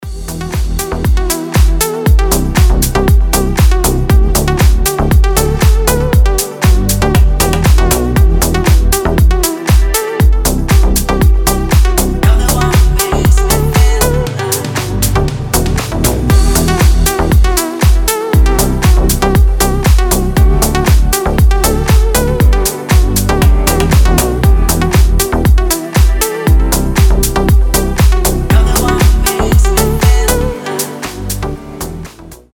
• Качество: 320, Stereo
deep house
мелодичные
приятные
Хорошее звучание всеми любимого дипа